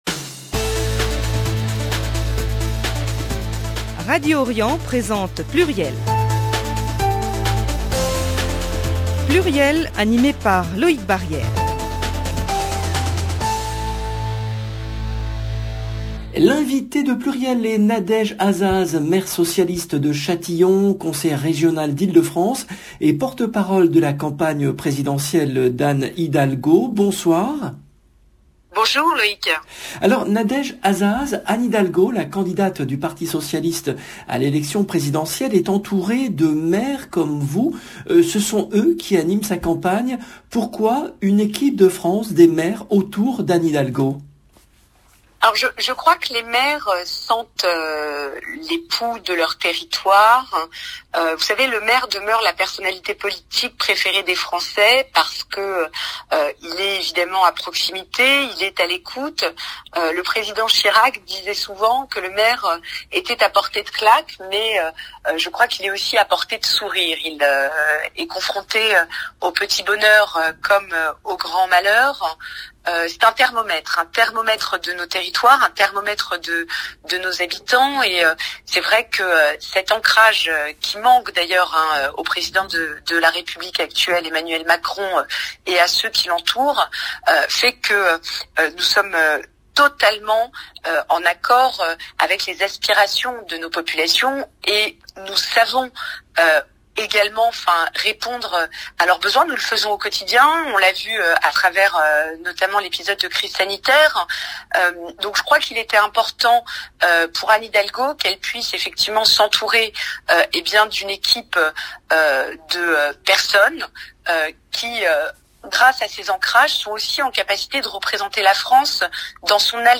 L’invitée de PLURIEL est Nadège Azzaz , maire socialiste de Châtillon, conseillère régionale d’Ile-de-France, et porte-parole de la campagne présidentielle d’Anne Hidalgo